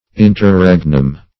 Interregnum \In`ter*reg"num\, n.; pl.